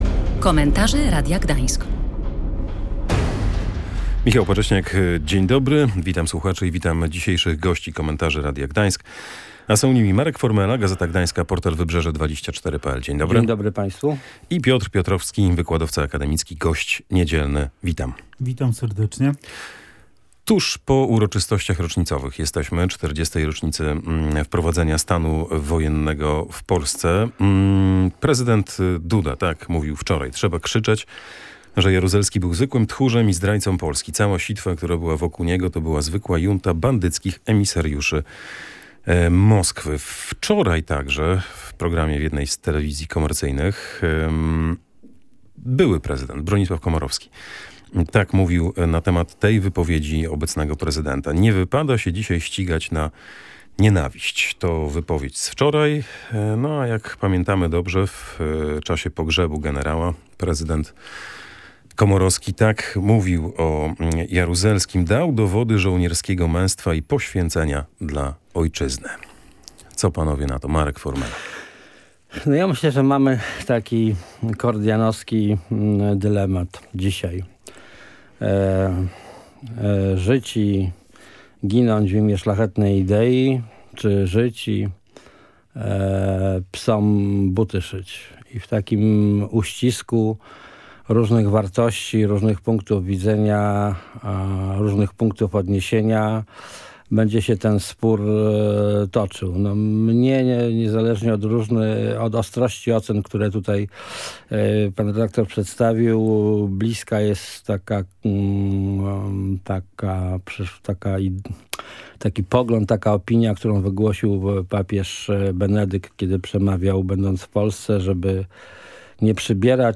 Chroni psa przed upałami i odmrożeniami. Ekspertka tłumaczy, jak dbać o sierść czworonogów